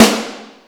Tuned snare samples Free sound effects and audio clips
• Small Reverb Steel Snare Drum Sound A Key 355.wav
Royality free snare tuned to the A note. Loudest frequency: 2011Hz
small-reverb-steel-snare-drum-sound-a-key-355-ta4.wav